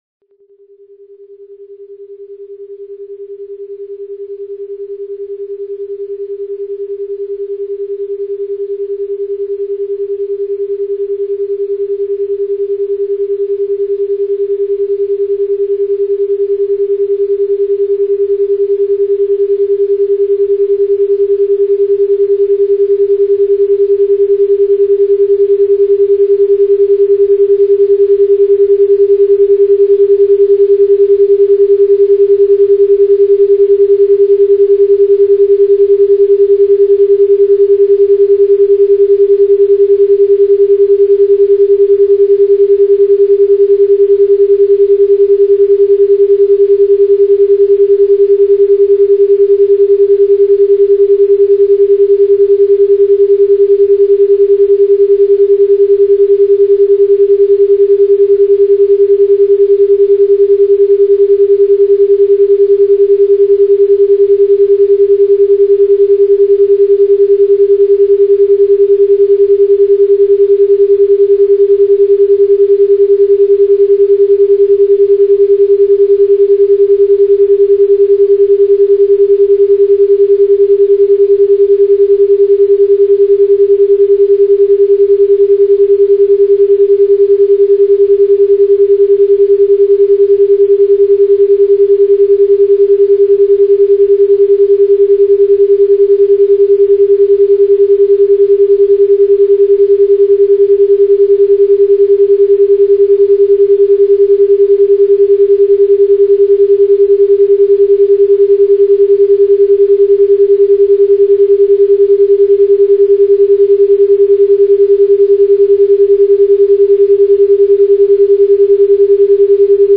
Цифровой наркотик (аудио наркотик) СЧАСТЬЕ